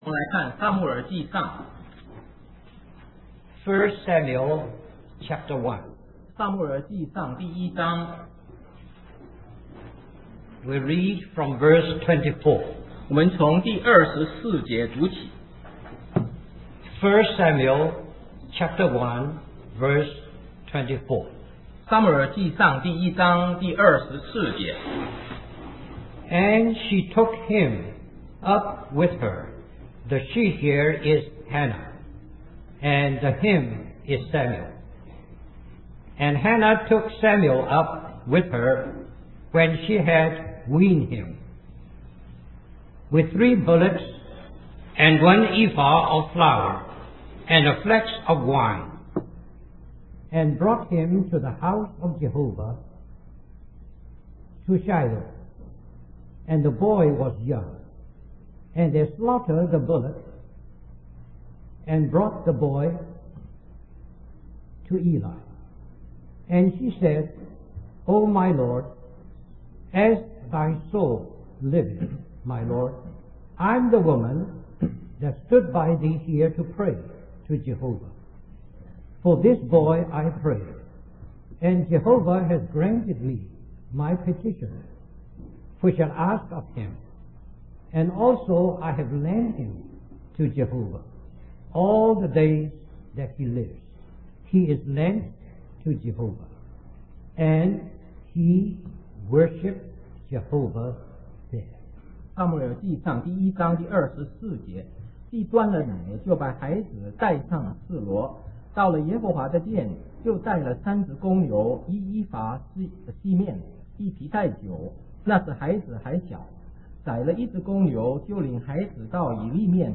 In this sermon, the preacher emphasizes the importance of having a personal relationship with God. He uses the story of Samuel in the Bible to illustrate how God can speak to individuals and raise them up for His purposes.